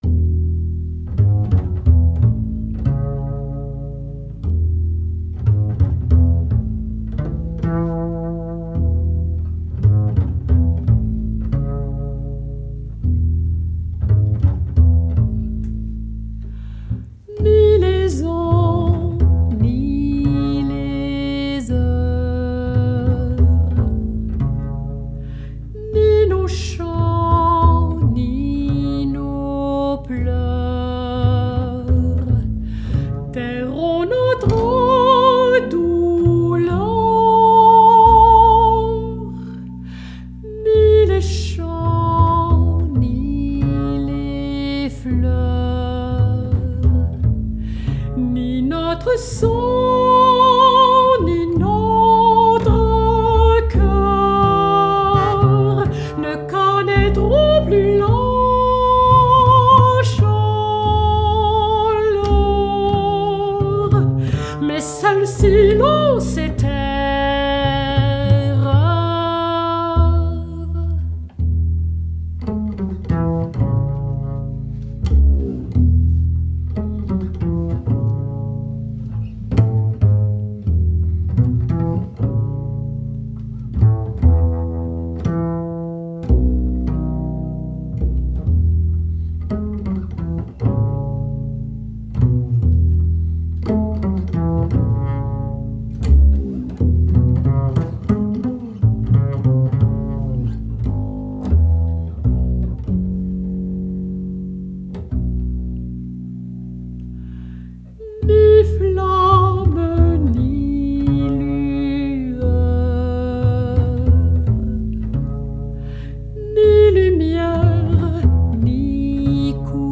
8 duos pour contrebasse et voix de mezzo-soprano
A la contrebasse le merveilleux